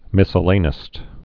(mĭsə-lānĭst, mĭ-sĕlə-)